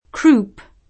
vai all'elenco alfabetico delle voci ingrandisci il carattere 100% rimpicciolisci il carattere stampa invia tramite posta elettronica codividi su Facebook croup [ingl. kr 2 up ] s. m. (in it.) med. — nome ingl. della difterite (o difteria) laringea — parzialm. adatt. in it. come crup [ krup ]